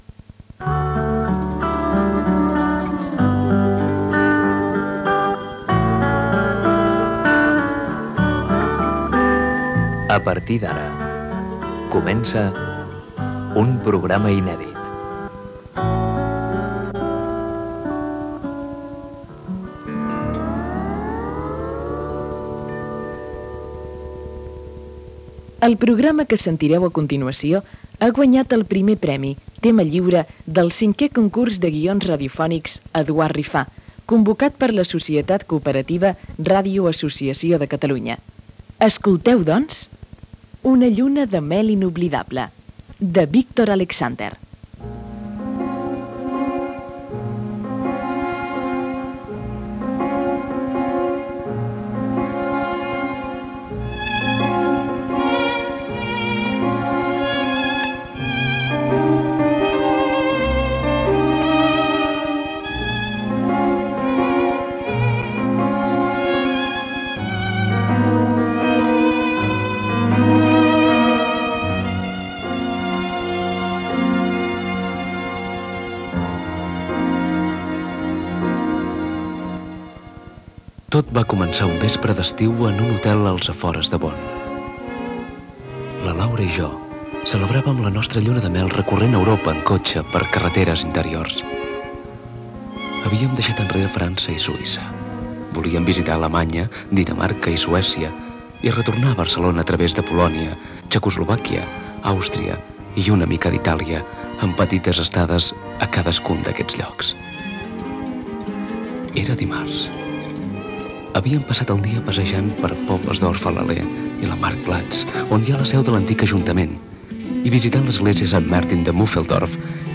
7b775544a16e0b254b59c126396ff149a3eec573.mp3 Títol Ràdio Associació RAC 105 Emissora Ràdio Associació RAC 105 Titularitat Pública nacional Nom programa Una lluna de mel inolvidable Descripció Careta i primers minuts de la ficció sonora. Gènere radiofònic Ficció